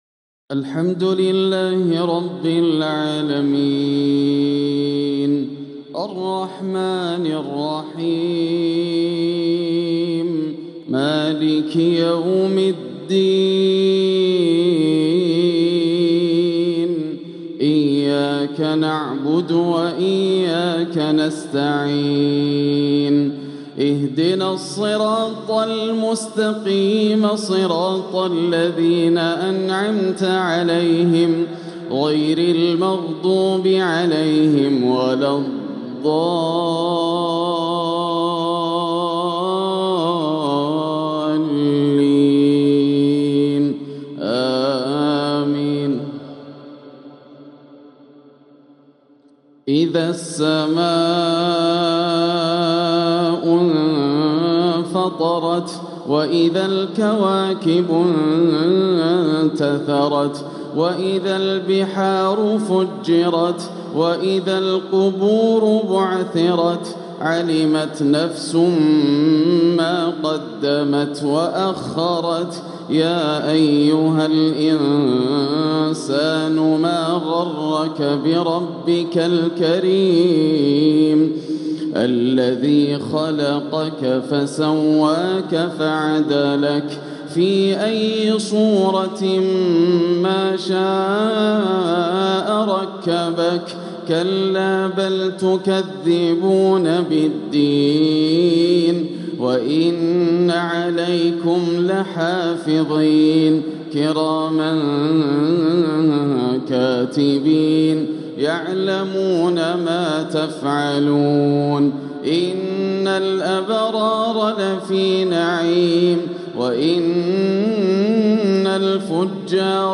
العقد الآسر لتلاوات الشيخ ياسر الدوسري تلاوات شهر رجب عام ١٤٤٦ هـ من الحرم المكي > سلسلة العقد الآسر من تلاوات الشيخ ياسر > الإصدارات الشهرية لتلاوات الحرم المكي 🕋 ( مميز ) > المزيد - تلاوات الحرمين